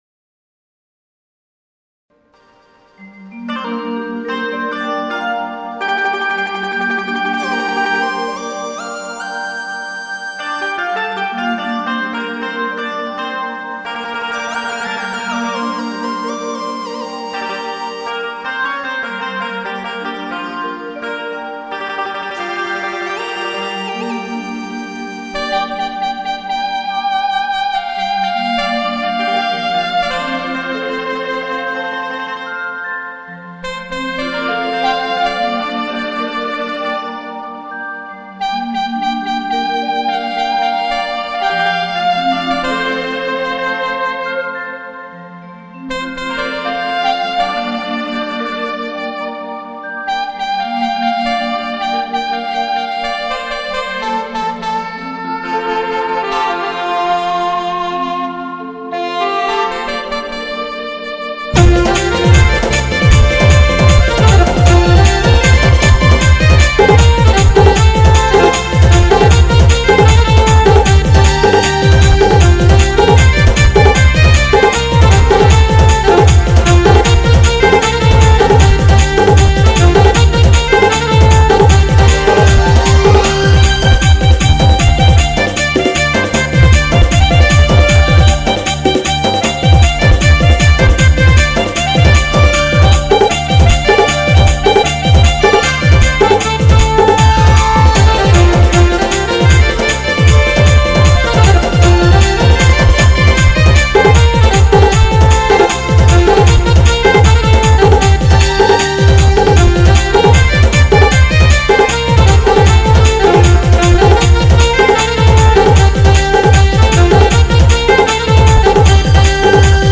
Rim jhim Pani Sambalpuri Instrumental
SAMBALPURI INSTRUMENT DJ REMIX